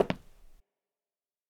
Wood2Left.wav